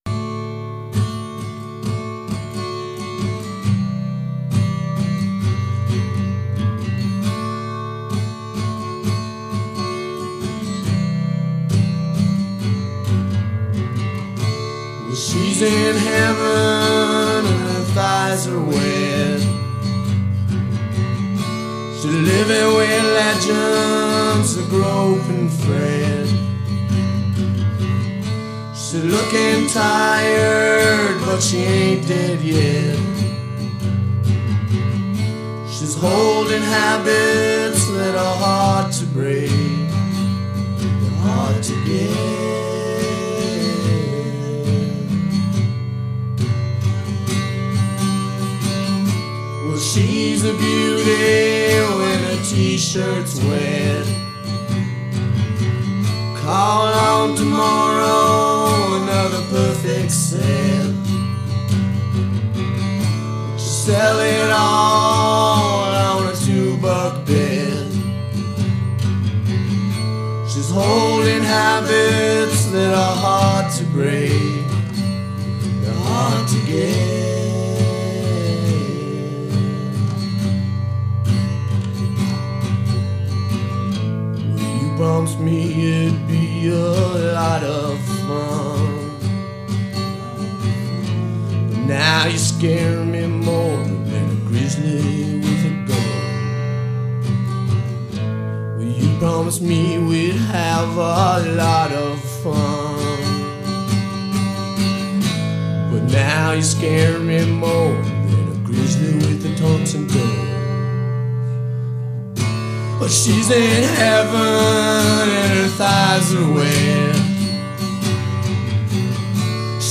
Recorded September 1987 to March 1990
Guitar, Vocals
Keyboards, Bass, Vocals